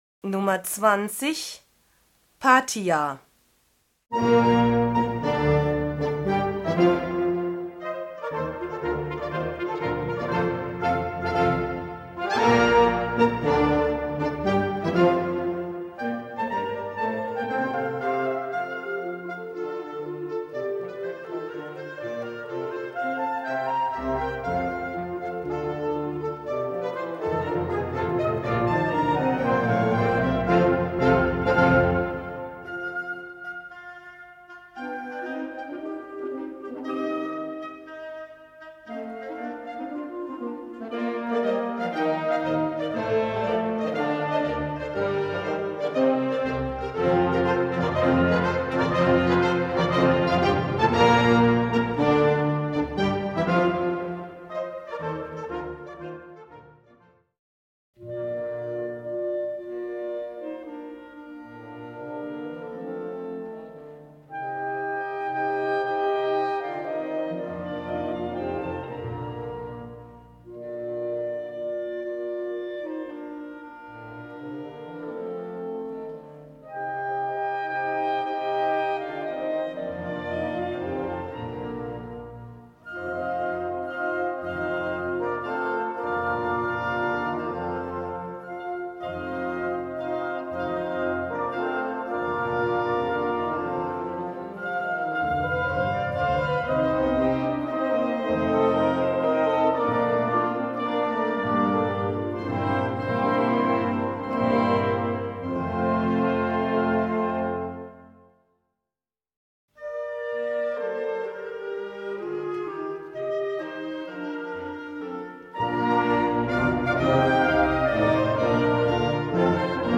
Gattung: Konzertante Bläsermusik
Besetzung: Blasorchester